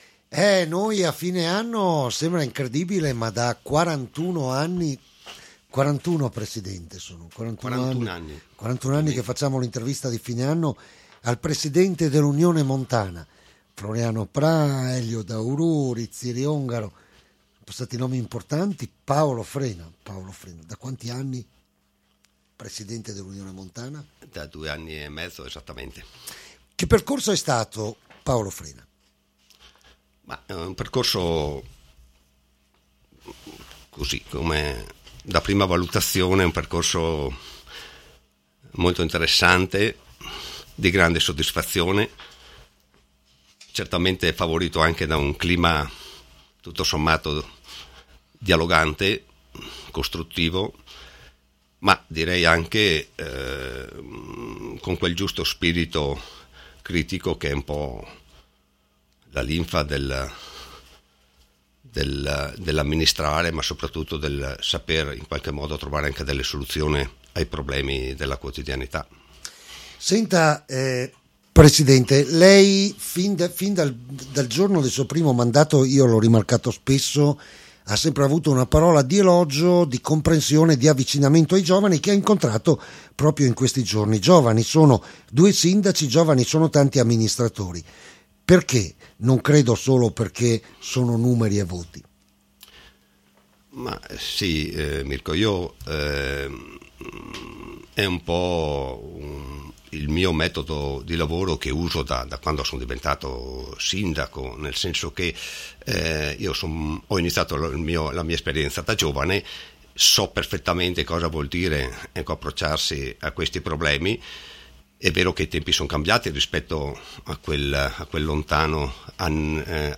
L’INTERVISTA DI FINE ANNO AL PRESIDENTE DELL’UNIONE MONTANA AGORDINA PAOLO FRENA